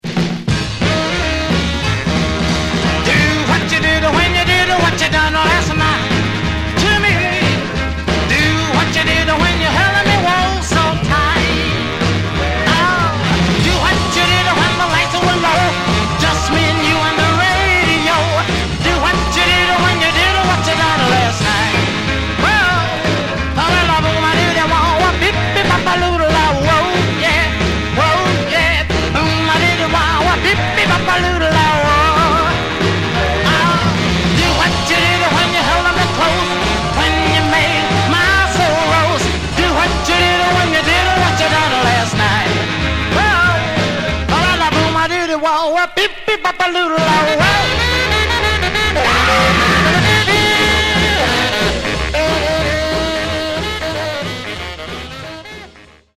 " and it has a rockin' character all its own.